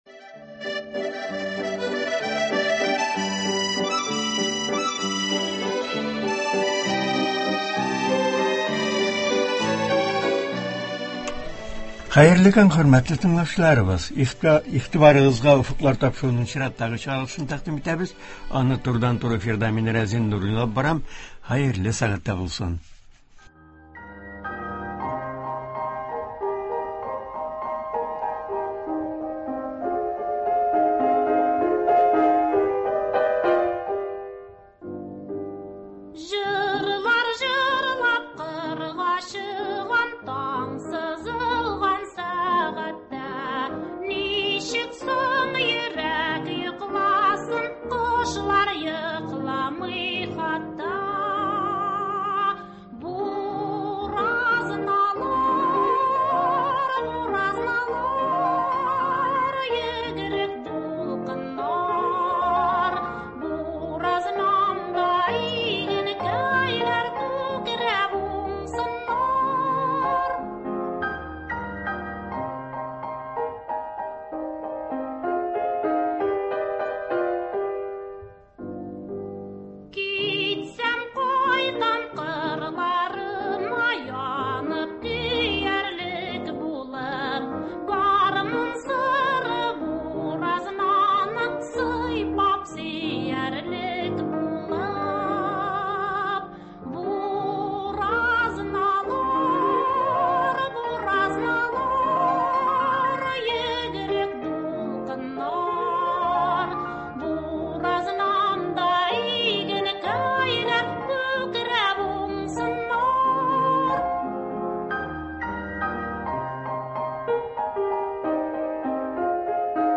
турыдан-туры эфирда